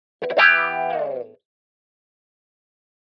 Guitar Thin Wah-Wah Finale Chord 2